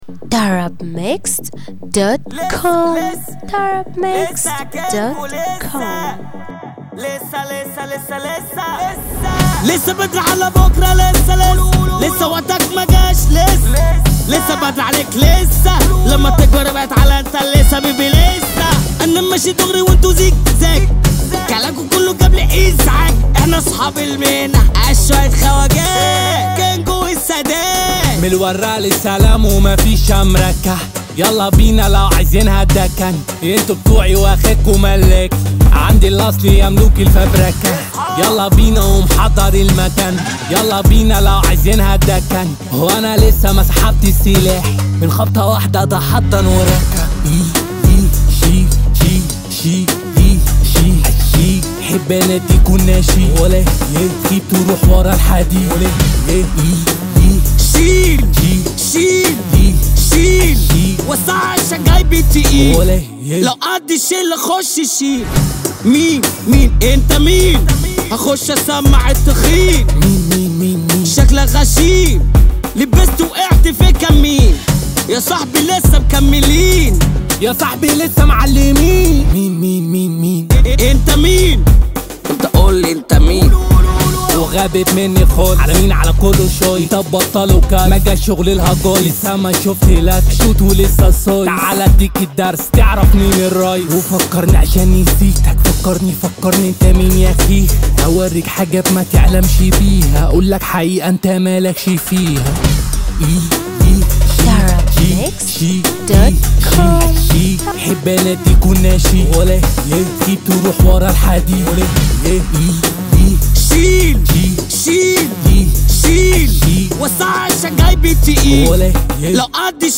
النوع : festival